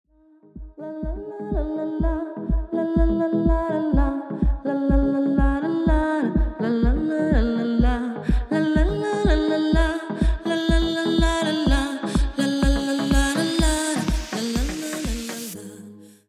• Качество: 128, Stereo
женский голос
нарастающие
Начало ремикса с мелодичным напевом